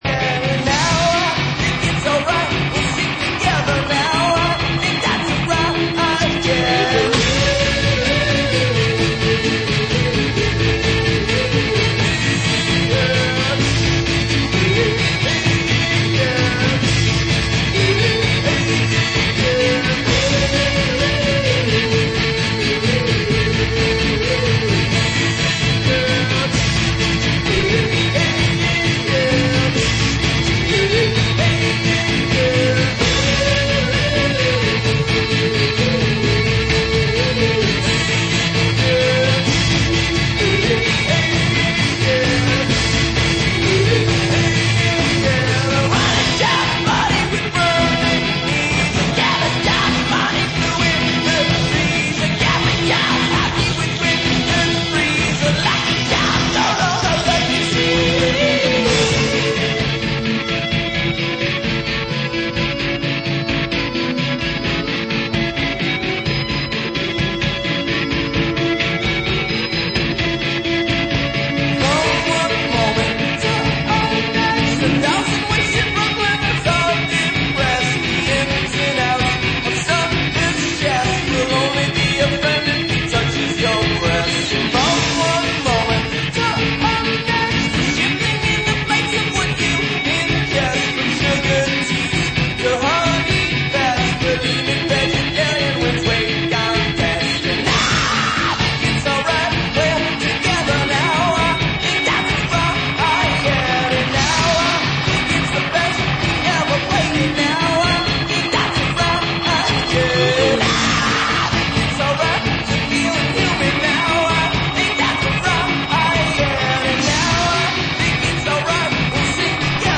weekly collage
an improvisation and a DJ set